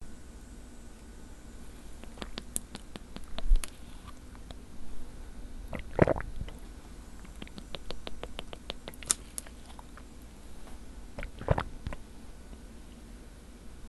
Water Drinking Sound Effect Free Download
Water Drinking